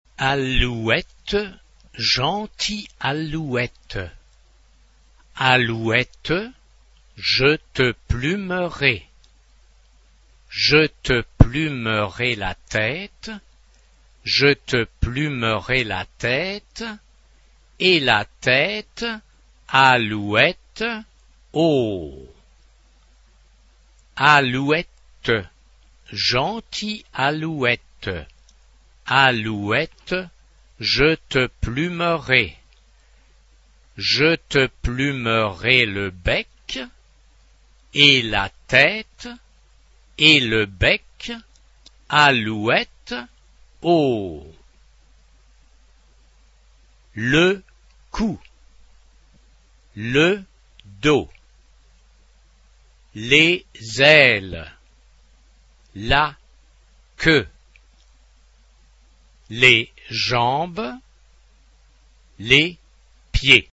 SATB (4 voix mixtes) ; Partition complète.
Consultable sous : Populaire Francophone Acappella
Tonalité : la majeur